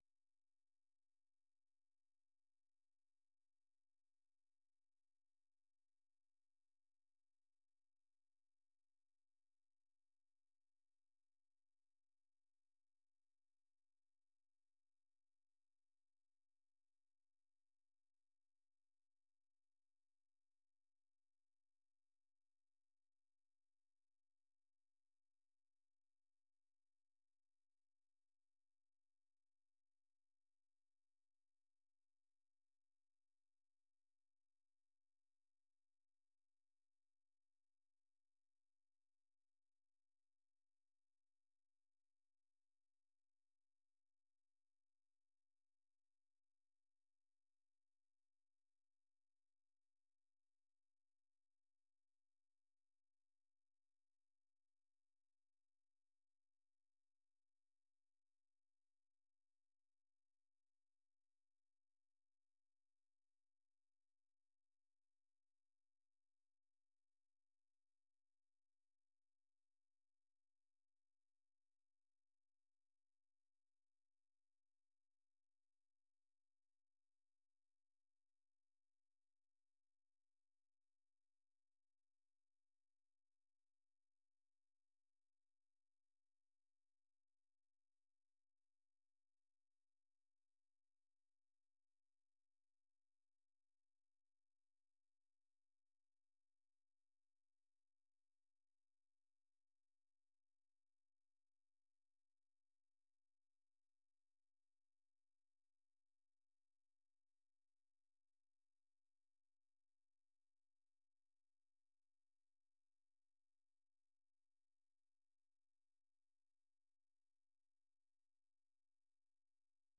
VOA 한국어 간판 뉴스 프로그램 '뉴스 투데이', 1부 방송입니다.